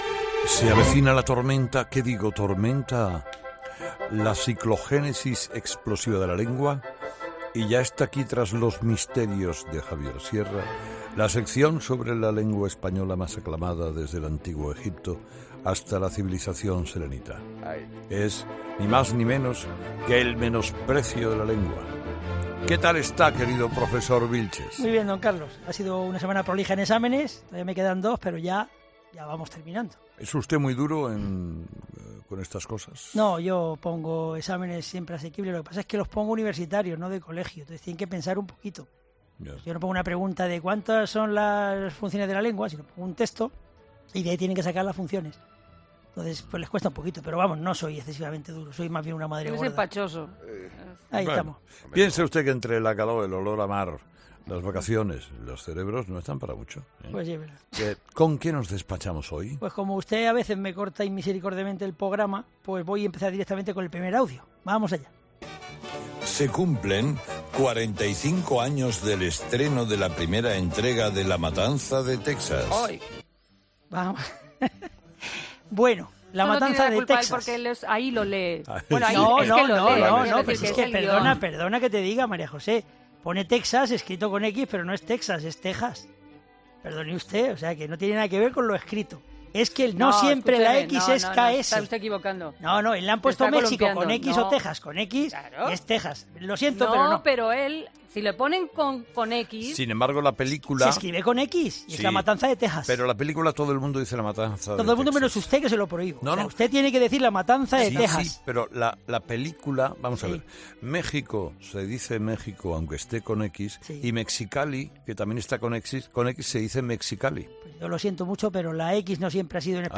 Hay muchas otras aclaraciones sobre la Lengua en la sección de este martes por eso te animamos a que escuches el audio y te advertimos de que, como siempre, terminamos con el chiste malo del profesor.